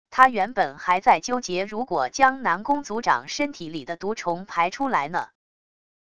她原本还在纠结如果将南宫族长身体里的毒虫排出来呢wav音频生成系统WAV Audio Player